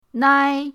nai1.mp3